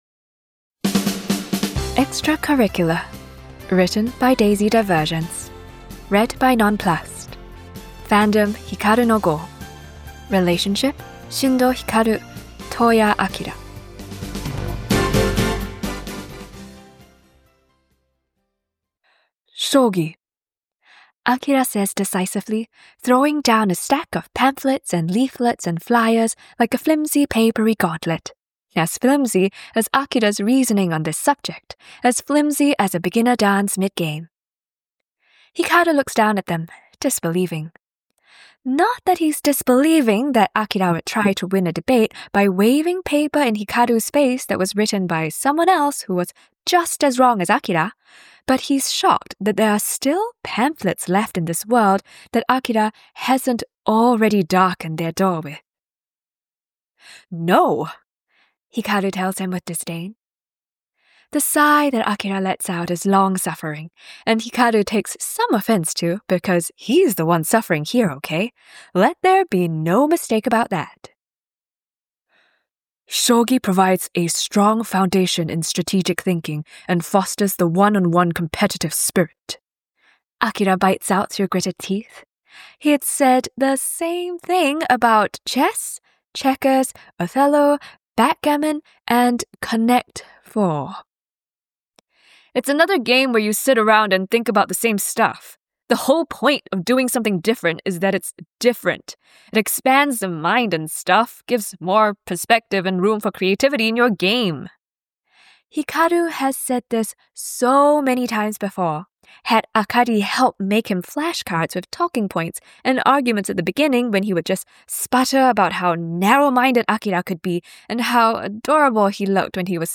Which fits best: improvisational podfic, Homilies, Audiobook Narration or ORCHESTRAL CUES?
improvisational podfic